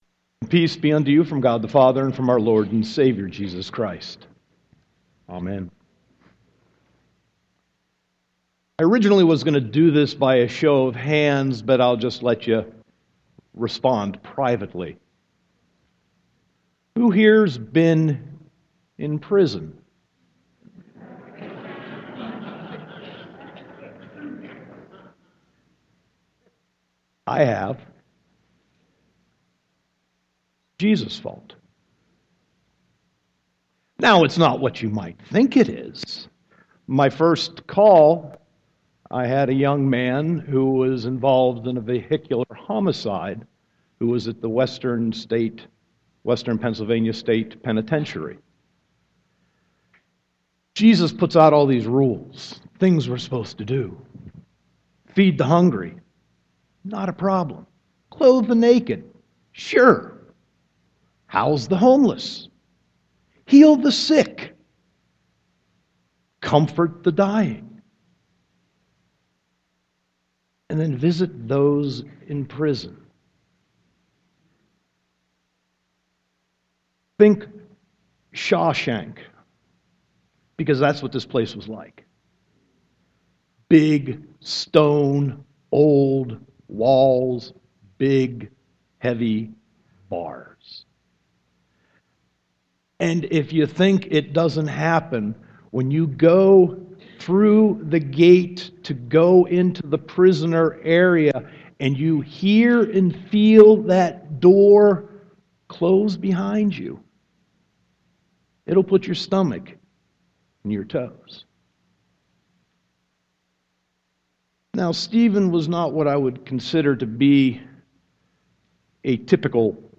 Sermon 2.28.2016